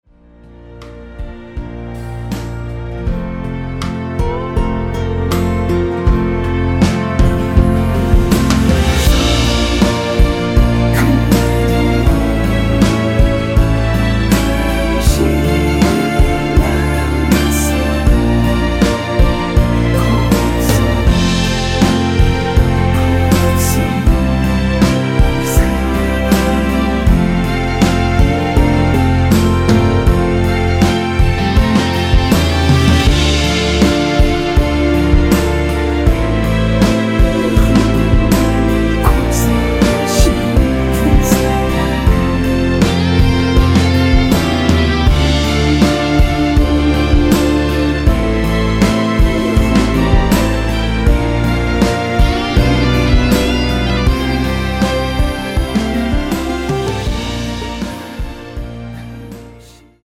원키에서(-1)내린 코러스 포함된 MR입니다.
앞부분30초, 뒷부분30초씩 편집해서 올려 드리고 있습니다.